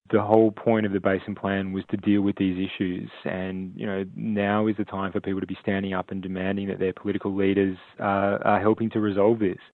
Water